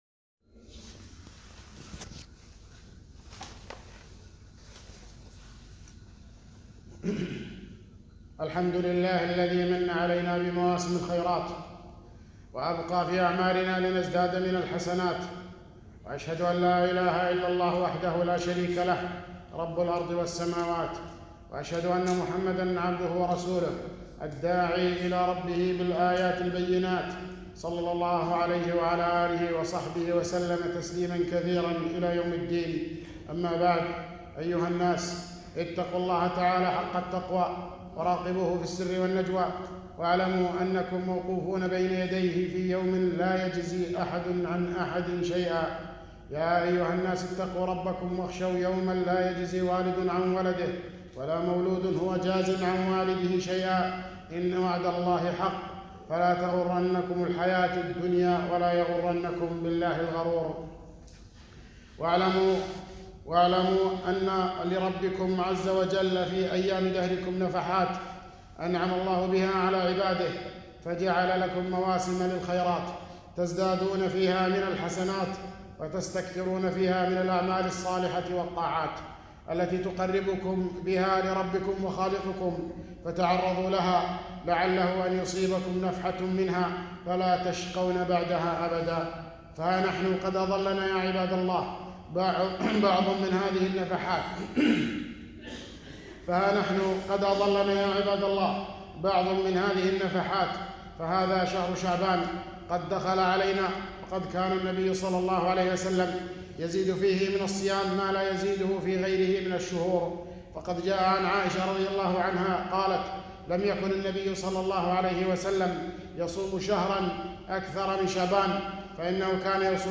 خطبة - شهر شعبان فضائل وأحكام